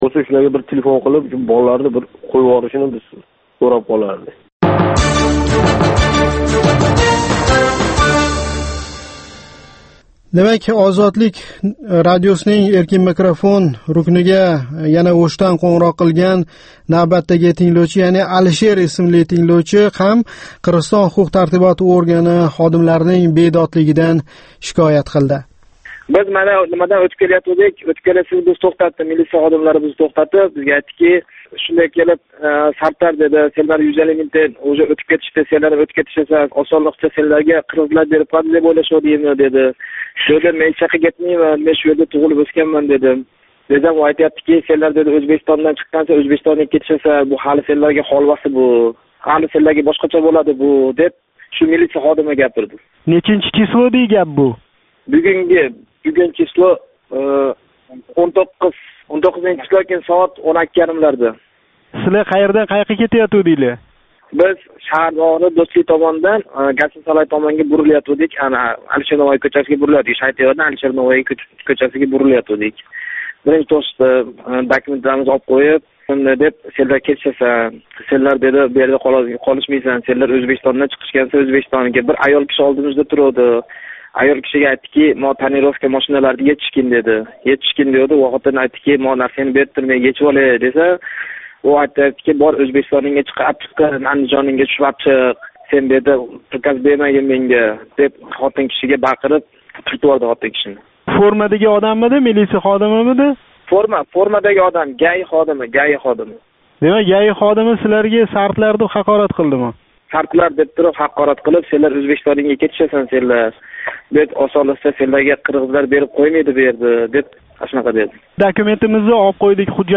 "7 кун - Ўзбекистон": Ҳафта давомида Ўзбекистон сиëсий¸ иқтисодий-ижтимоий ҳаëти¸ қолаверса мамлакатдаги инсон ҳуқуқлари ва демократия вазияти билан боғлиқ долзарб воқеалардан бехабар қолган бўлсангиз "7 кун - Ўзбекистон" ҳафталик радиожурналимизни тинглаб боринг. Бу туркум ҳафтанинг энг муҳим воқеалари калейдоскопидир!